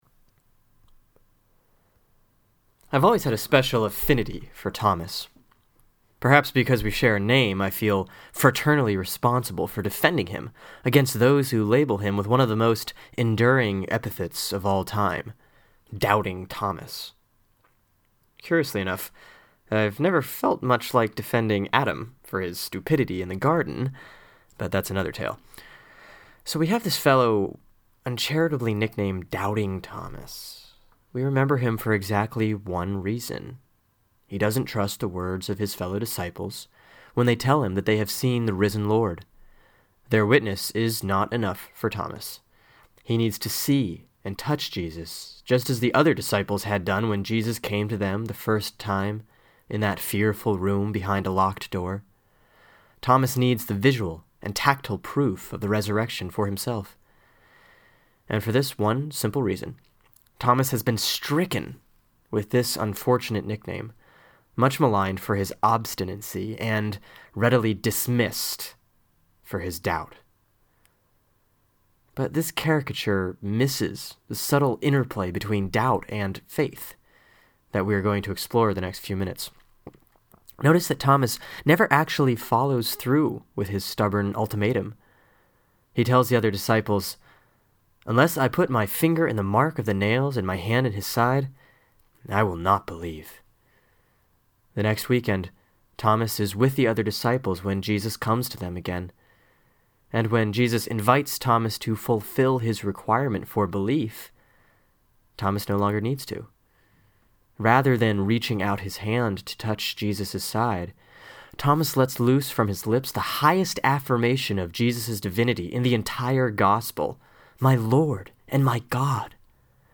(Sermon for April 11, 2010 || Easter 2, Year C, RCL || John 20:19-31)